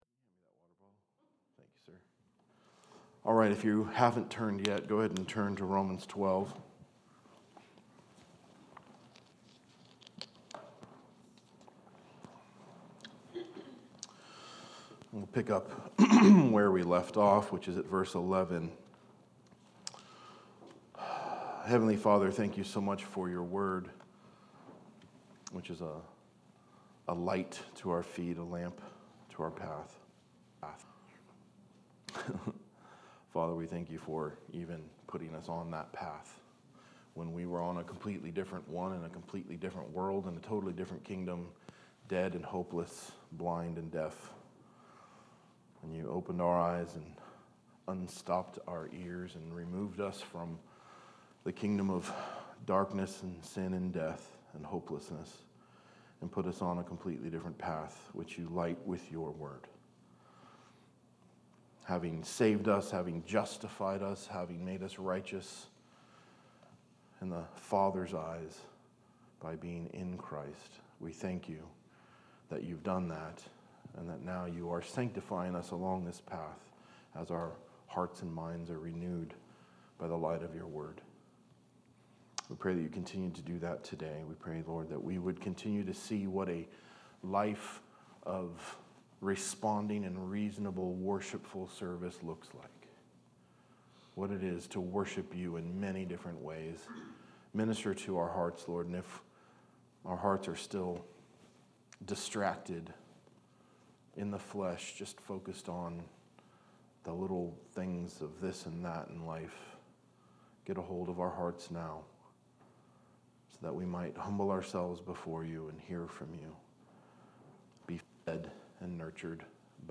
A message from the series "Biblical Worship Series."